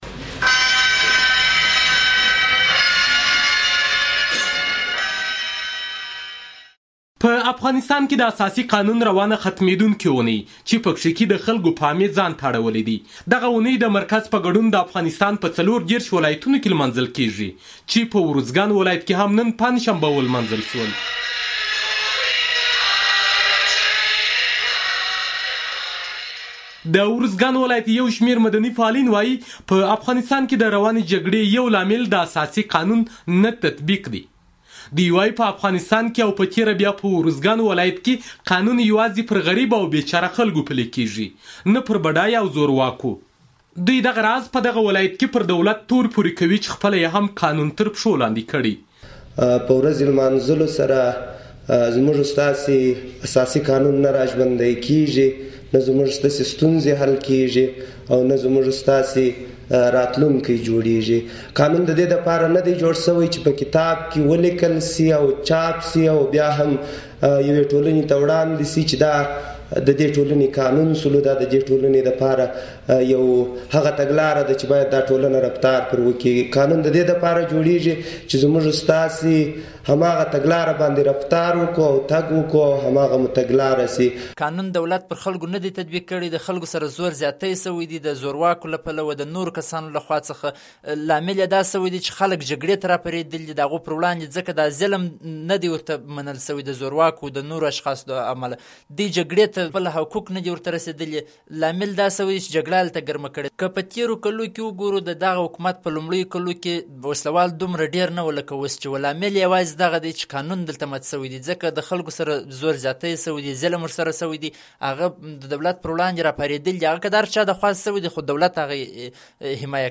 د ارزګان راپور